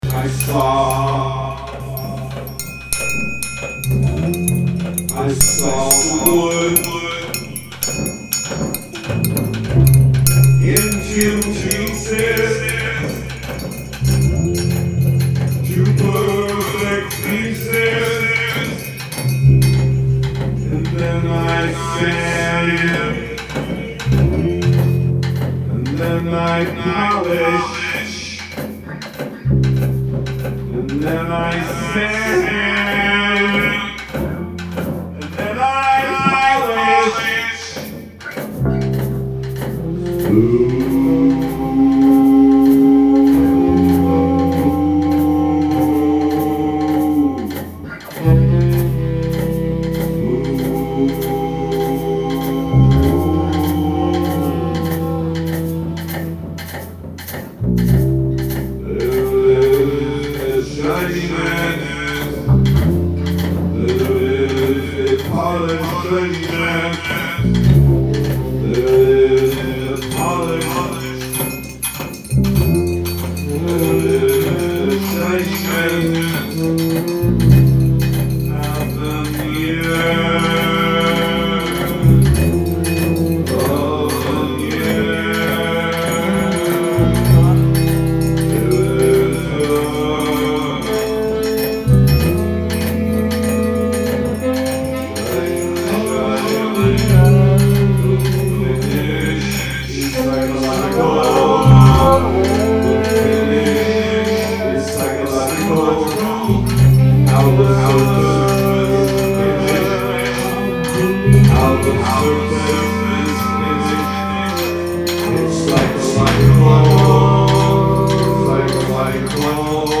ALL MUSIC IS IMPROVISED ON SITE
Wood Shop guitar/voice
triangle/shaker/voice
tenor sax
drums
bass